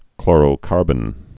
(klôrō-kärbən)